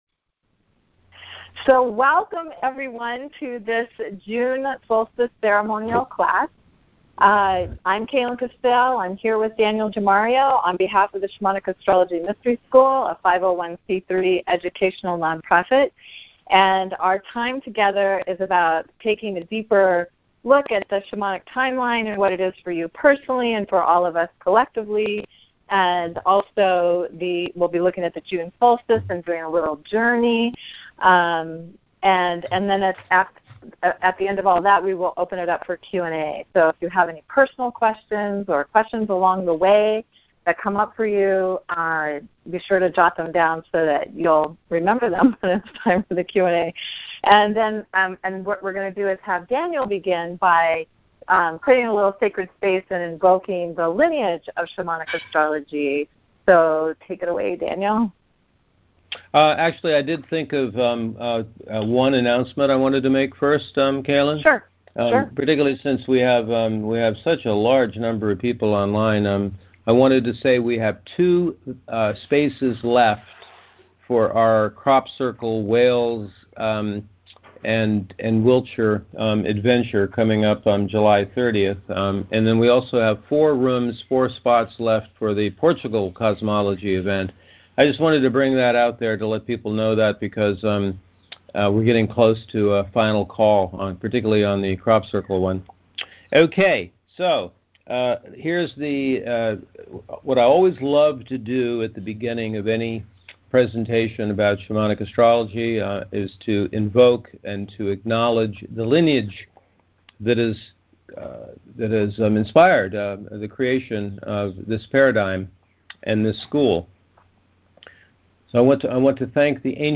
Please see the Recording and Free Handout below for the June Solstice Teleclass that took place on June 17, 2017 (2 hours, 7 minutes)